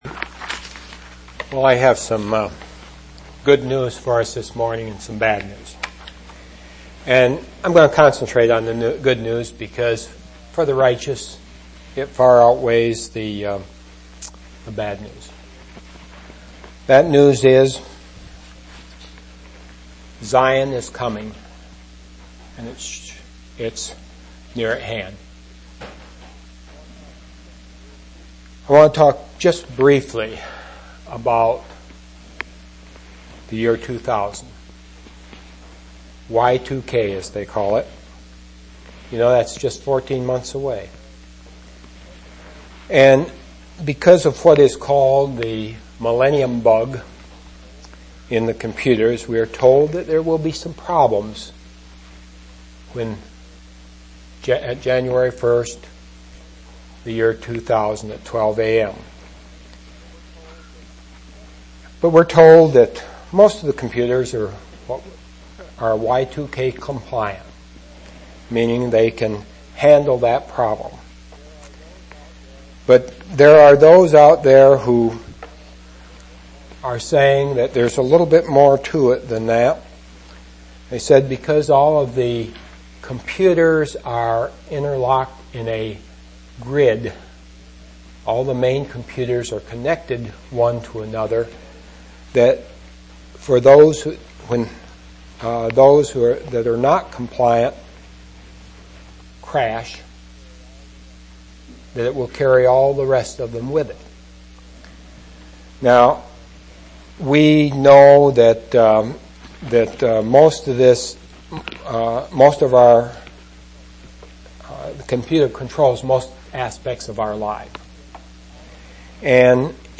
10/18/1998 Location: East Independence Local Event